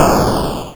dash.wav